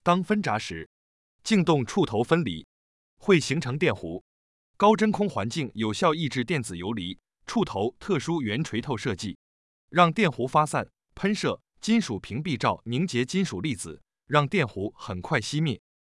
真空断路器-VS1-燃弧息弧.mp3